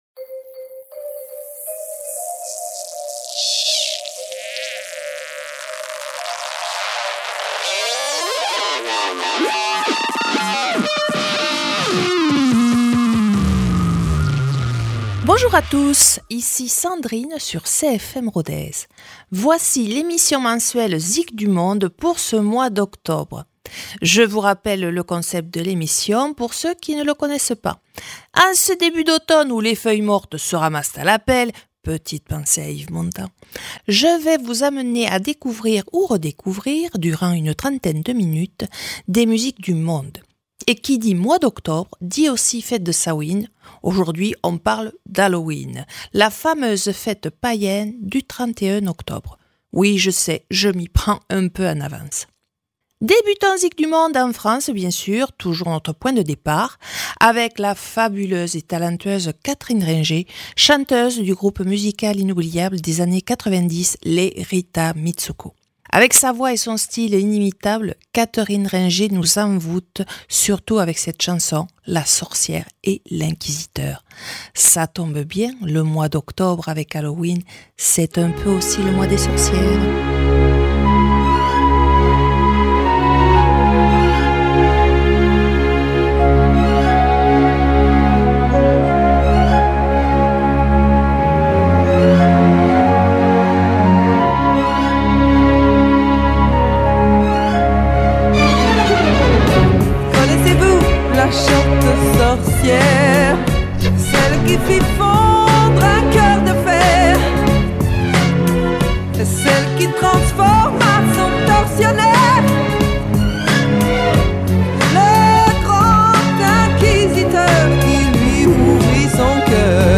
En automne, on continue un voyage musical "halloweenesque".